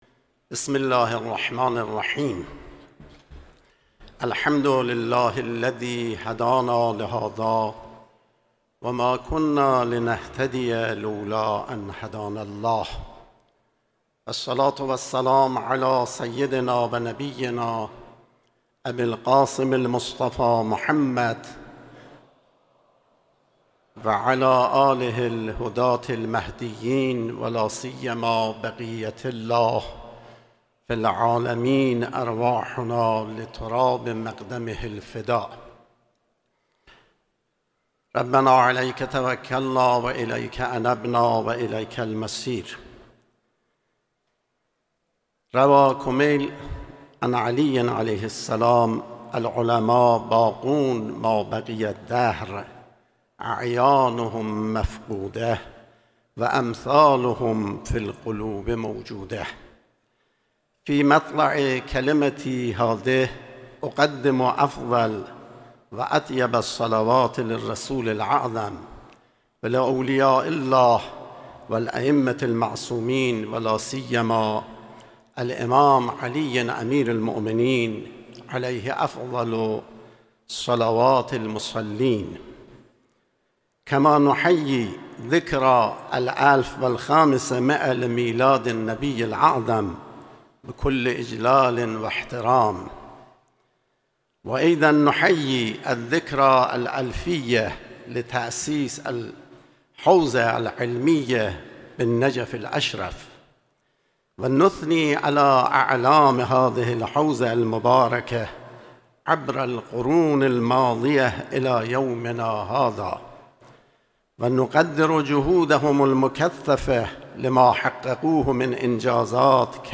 فیلم کامل سخنرانی آیت الله اعرافی به زبان عربی در کنگره میرزای نائینی در نجف اشرف
فیلم کامل سخنرانی آیت الله اعرافی، مدیر حوزه های علمیه به زبان عربی در کنگره بین المللی آیت الله العظمی میرزای نائینی در شهر نجف اشرف.